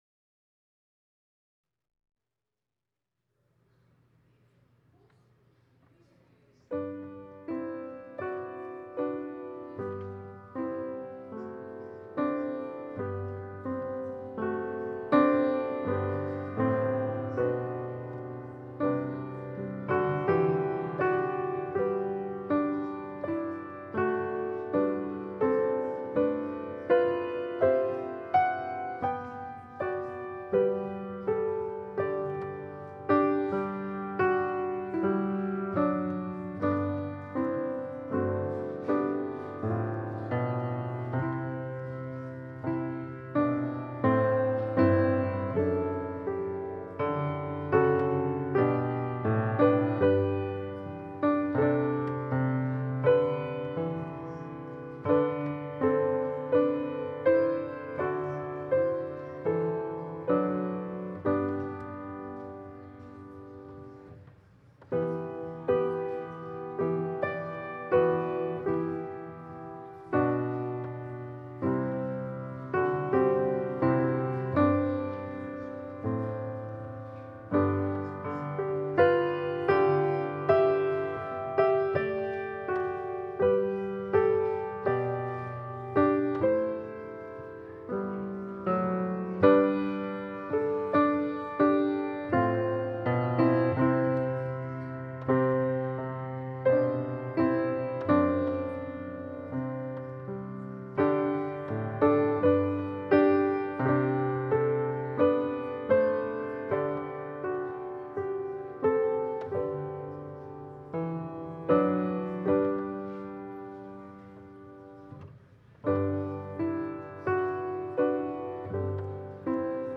Passage: Matthew 2: 1-12 Service Type: Holy Day Service Scriptures and sermon from St. John’s Presbyterian Church on Sunday